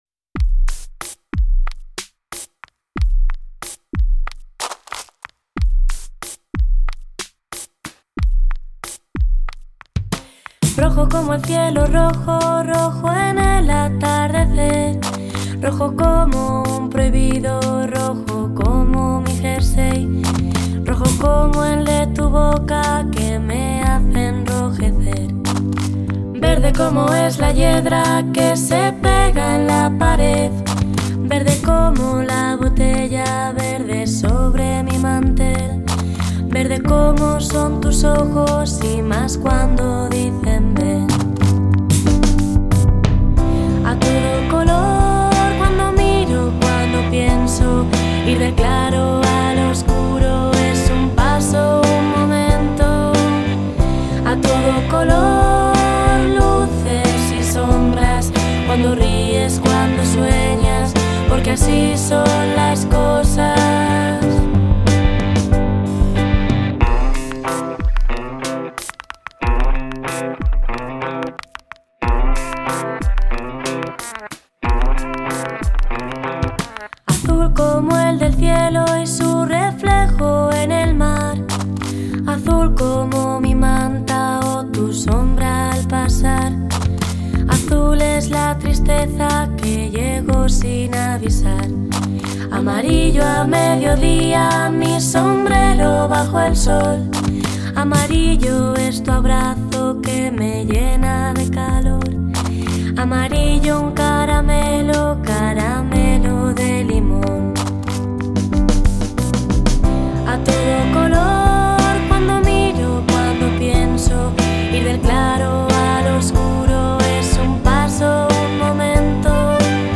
依然是我喜欢的甜美可人的小女生，顽皮而略带羞涩
同时他们尝试使用80年代复古音乐某些元素